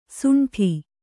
♪ suṇṭhi